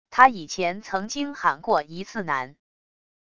他以前曾经喊过一次男wav音频